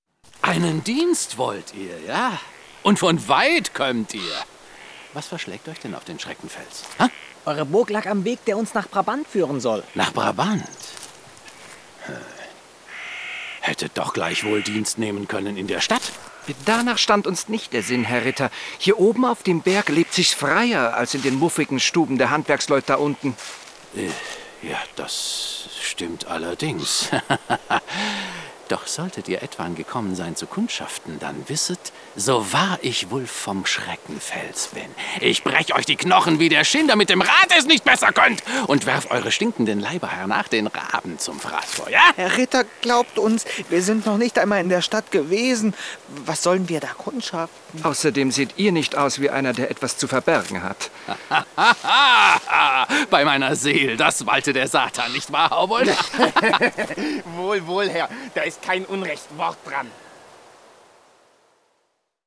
Profi-Sprecher deutsch.
Sprechprobe: Sonstiges (Muttersprache):
german voice over artist